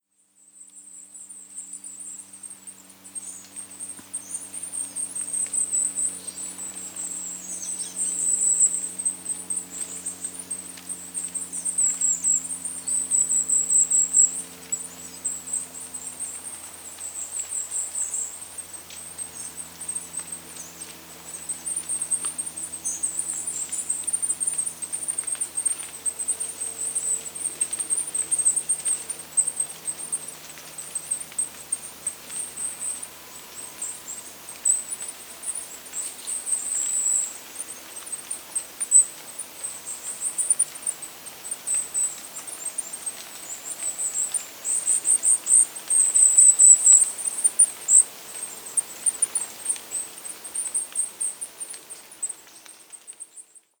goudhaan
♪ roep herfst
goudhaantje_roep_herfst.mp3